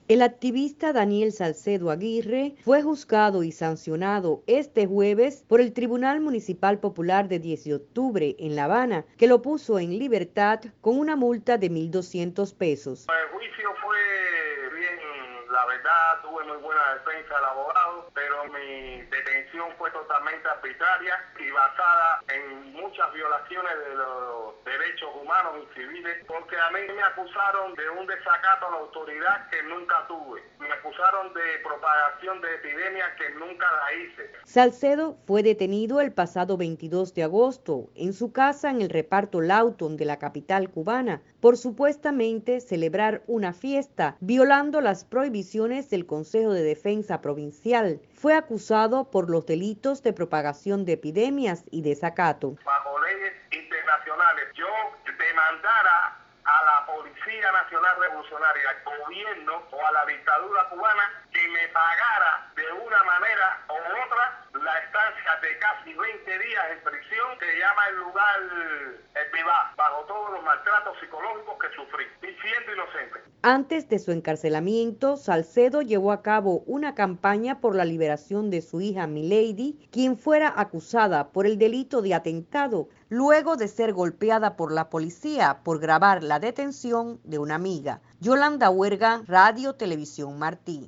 en entrevista con Radio Martí